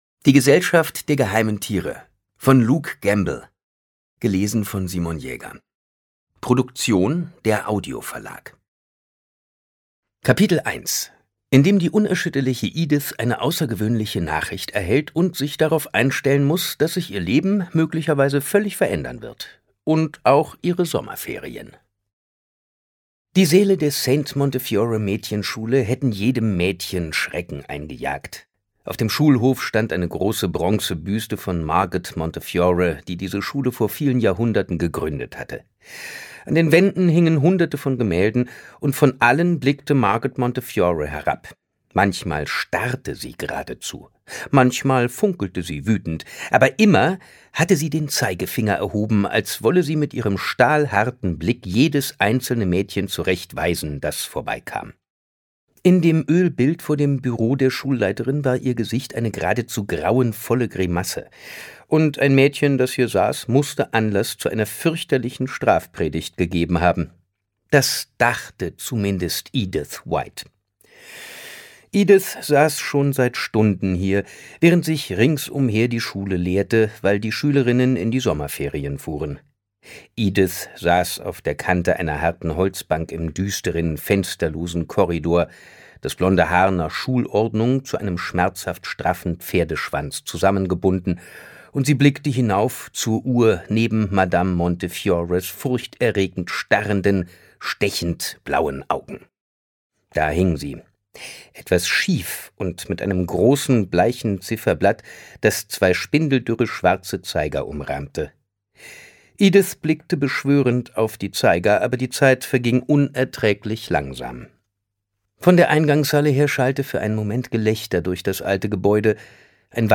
Teil 1. Ungekürzte Lesung mit Simon Jäger (1 mp3-CD)
Simon Jäger (Sprecher)
Simon Jäger versteht es meisterhaft, mit seiner Stimme unterschiedliche Stimmungen zu inszenieren.